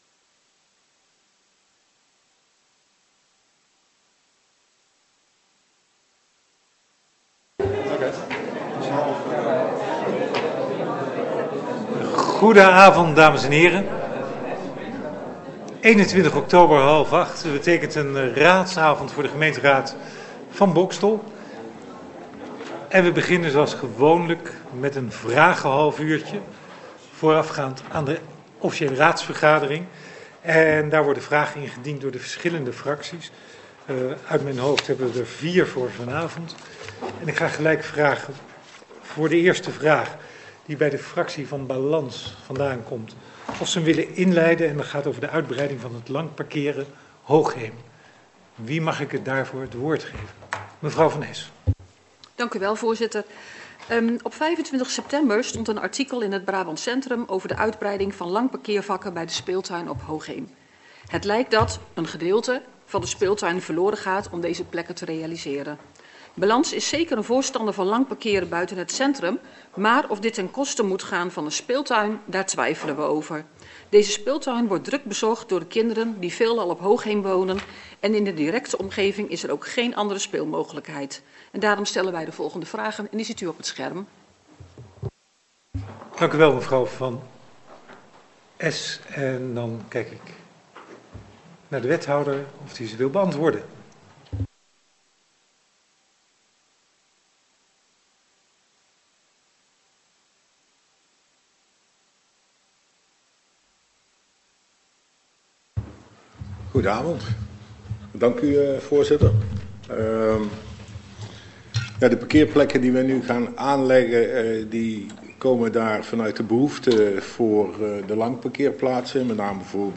Locatie Raadzaal Boxtel Voorzitter Ronald van Meygaarden Toelichting Wilt u de raadsvergadering als toehoorder volgen?
Agenda documenten Agendabundel 17 MB Geluidsopname raadsvergadering 21 oktober 2025 52 MB